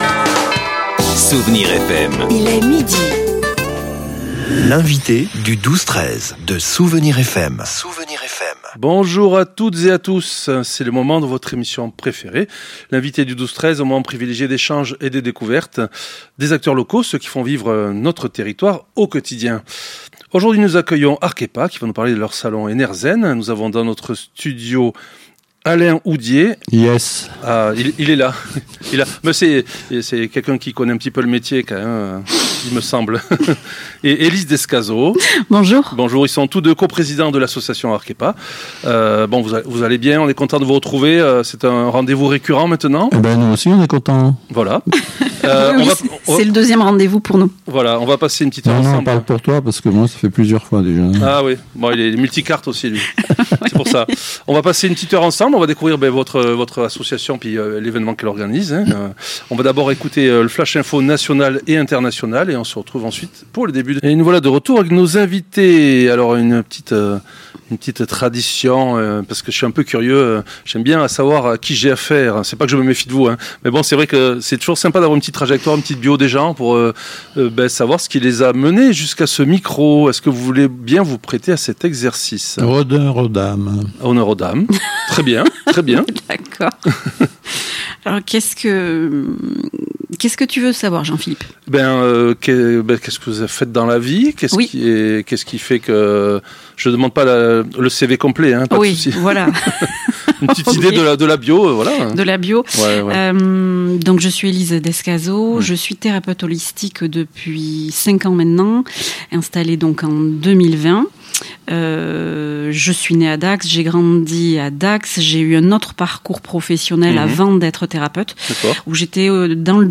L'invité(e) du 12-13 accueillait aujourd'hui ARKHEPA, l'association qui organise le salon ENERZEN ce week-end dans la salle Amélie Charrière à Dax.